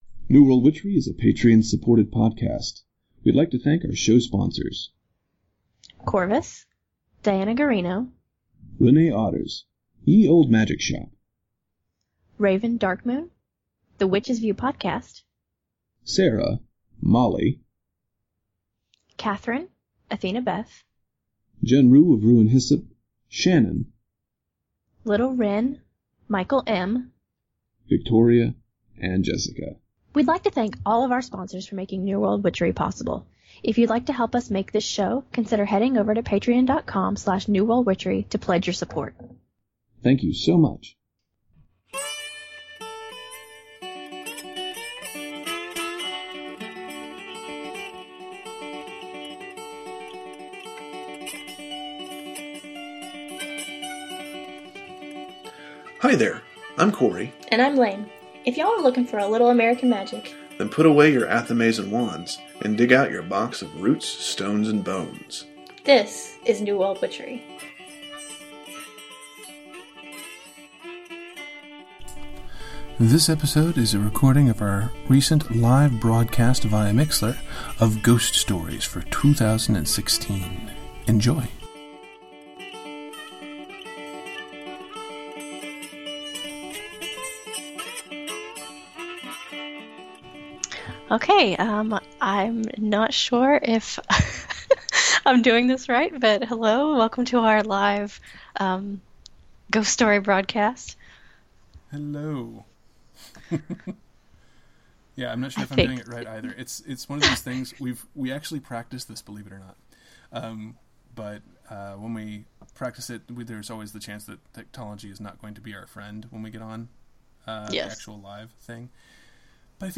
This is the downloadable podcast version of our recent MIxlr broadcast, which featured listener ghost stories for the spooky Hallows/late Autumn season.